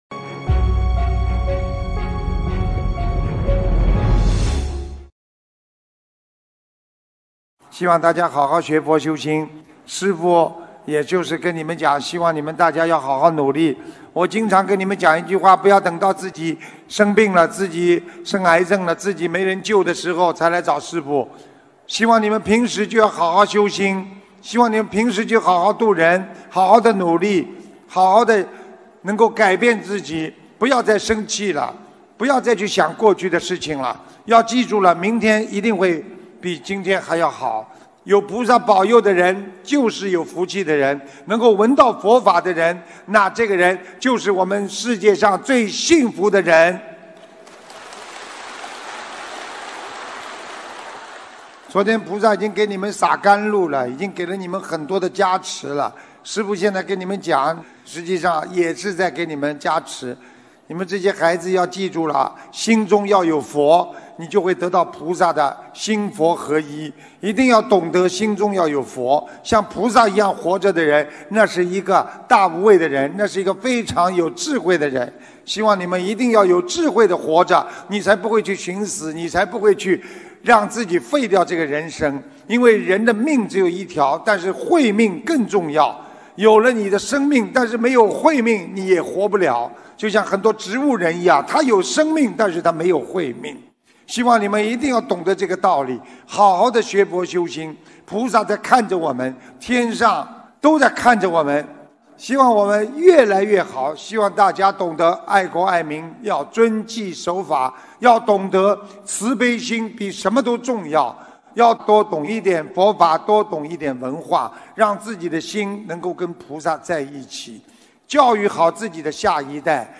2019年5月10日新加坡世界佛友见面会结束语-经典感人开示节选